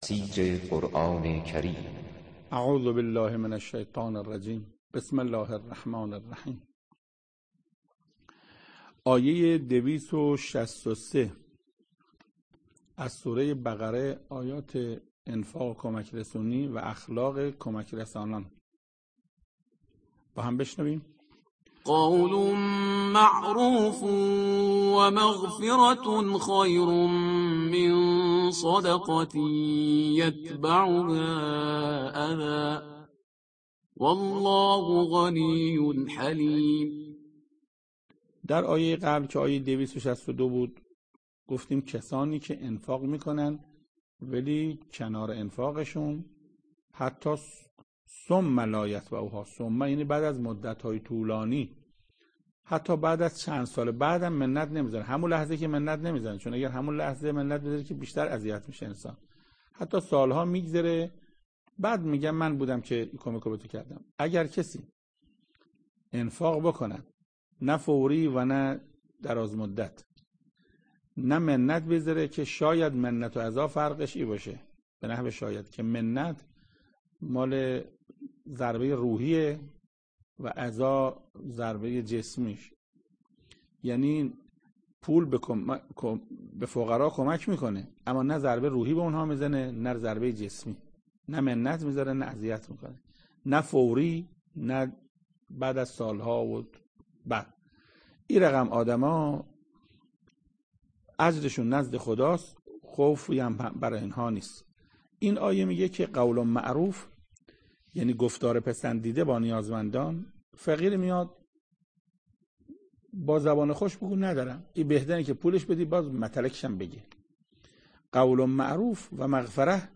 تفسیر آیه 263 سوره بقره - استاد محسن قرائتی در این بخش از ضیاءالصالحین، صوت تفسیر آیه دویست و شصت و سوم سوره مبارکه بقره را در کلام حجت الاسلام استاد محسن قرائتی با شما قرآن دوستان عزیز به اشتراک می گذاریم.